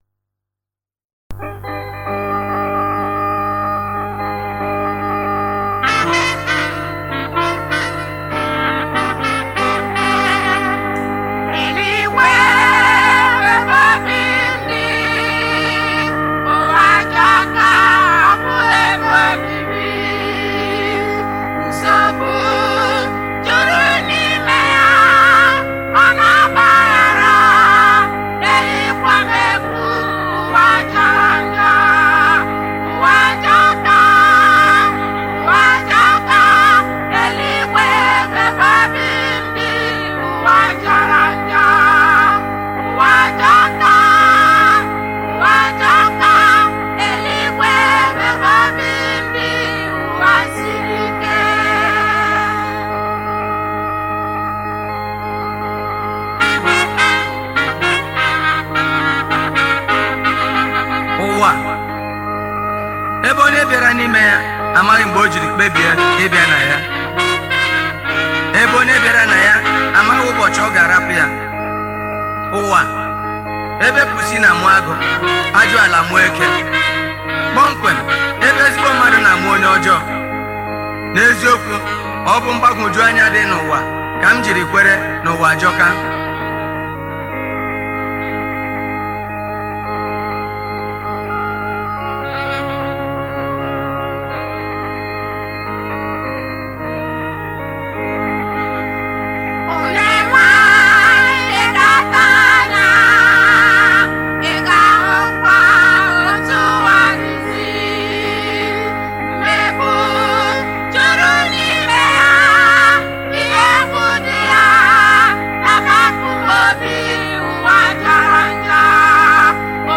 February 21, 2025 Publisher 01 Gospel 0